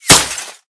glass04hl.ogg